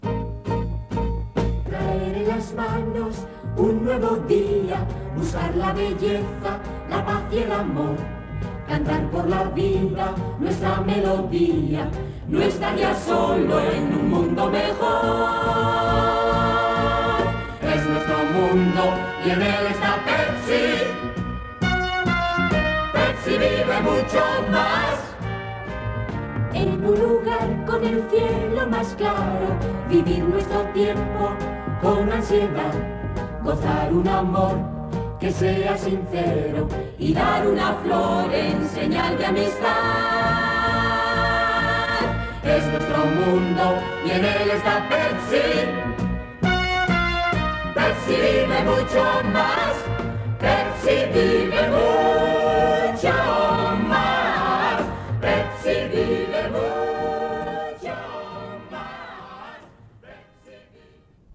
Publicitat cantada